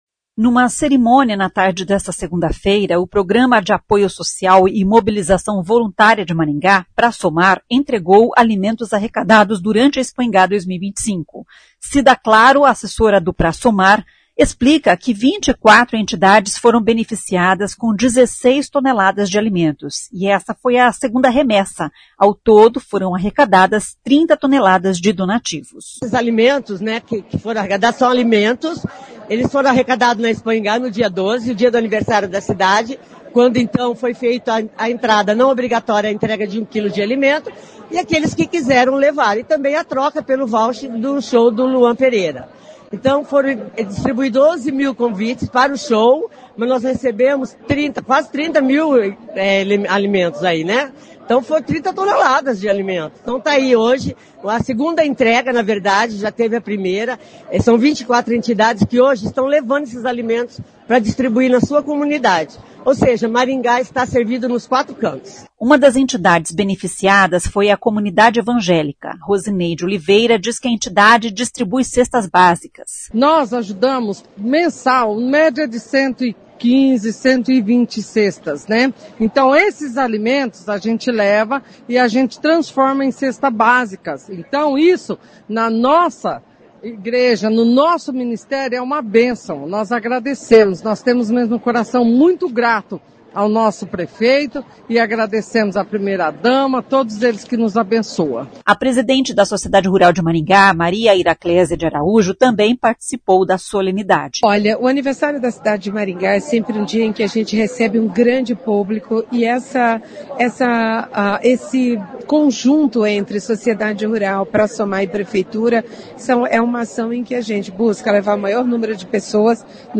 Numa cerimônia na tarde desta segunda-feira (26), o Programa de Apoio Social e Mobilização Voluntária de Maringá, Pra Somar, entregou alimentos arrecadados durante a Expoingá 2025.